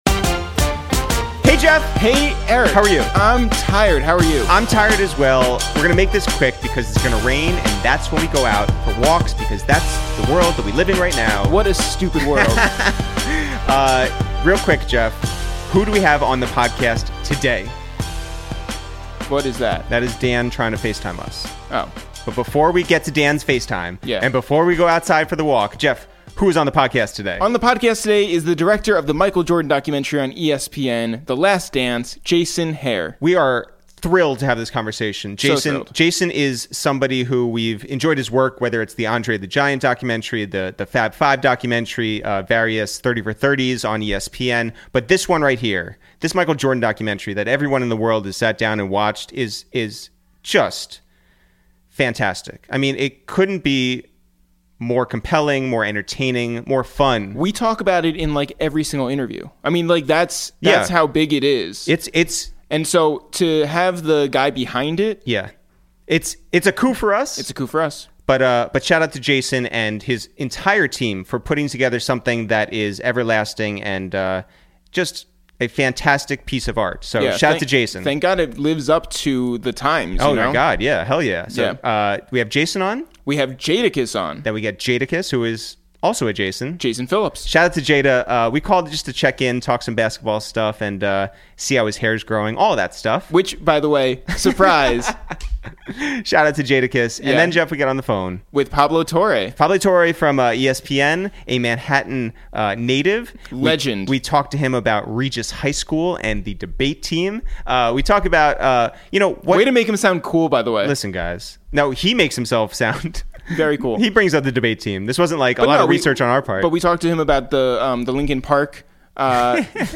Today on Episode 46 of Quarantine Radio we make calls from our Upper West Side apartment to check in on the director of ESPN's Michael Jordan documentary The Last Dance, Jason Hehir - we talk about growing up in Newton, Massachusetts mimicking Jordan's moves and listening to R...